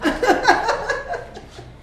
描述：一个女人轻轻地笑着。
Tag: 女性 幽默 搞笑